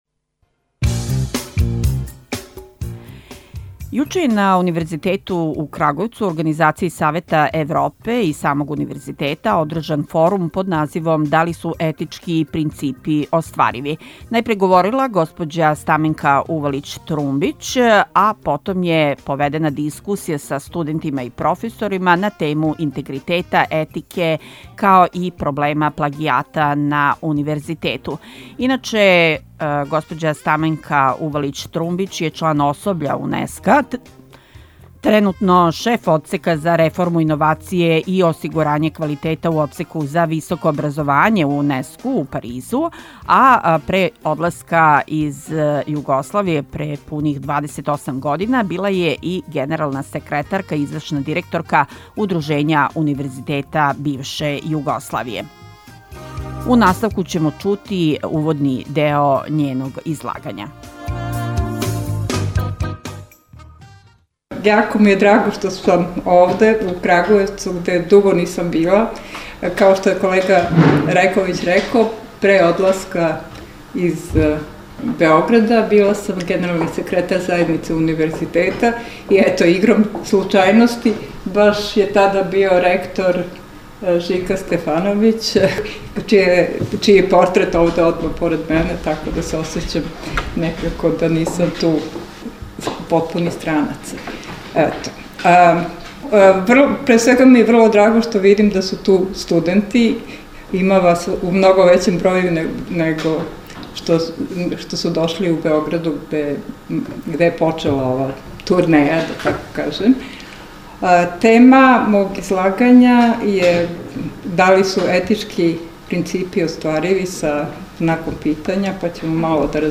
Да ли су етички принципи оствариви, била је тема форума одржаног у Ректорату Универзитета у Крагујевцу у организацији Савета Европе пред само 14 присутних.